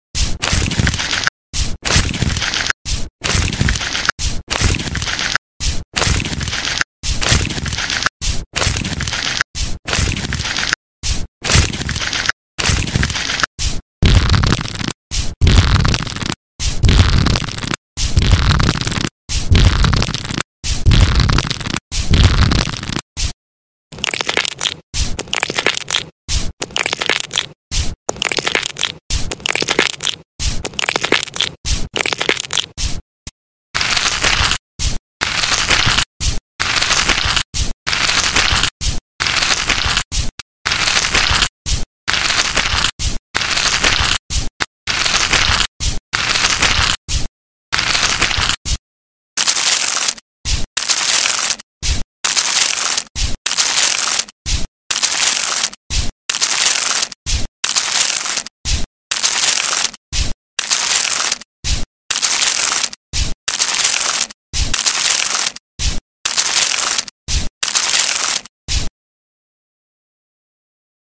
ASMR shooting foot spa therepy sound effects free download
ASMR ultimate tingling relaxation